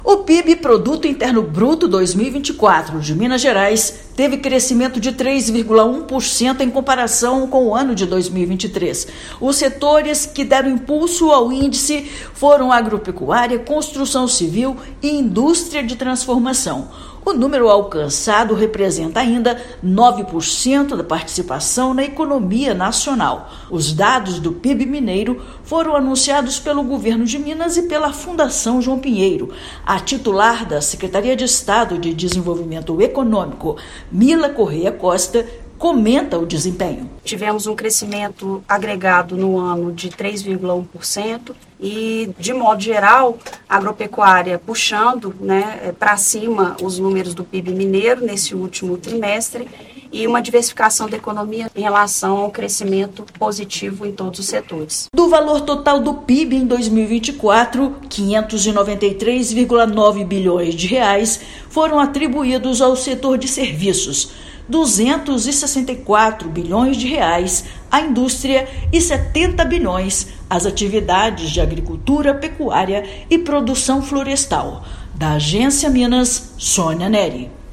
Economia mineira ultrapassou R$ 1 trilhão e atingiu marca de 9% de participação na economia nacional. Ouça matéria de rádio.